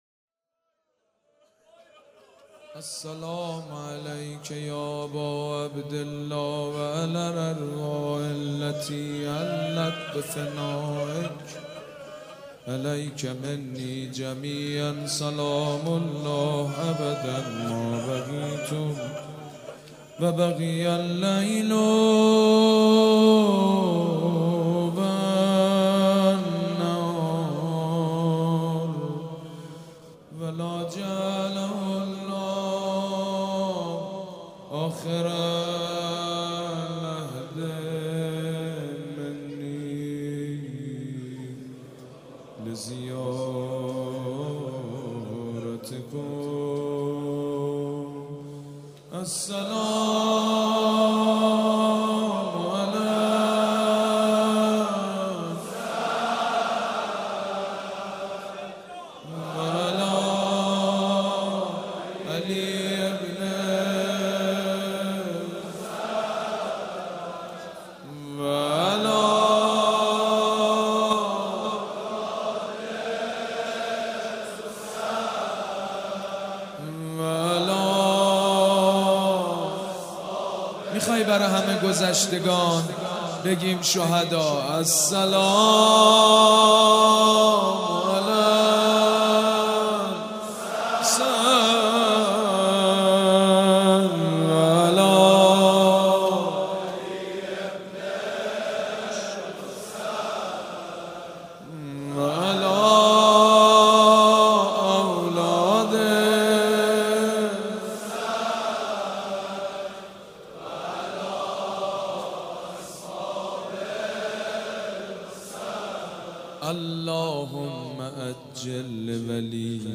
روضه
شب هفتم محرم الحرام‌ شنبه ۱7 مهرماه ۱۳۹۵ هيئت ريحانة الحسين(س)
مداح حاج سید مجید بنی فاطمه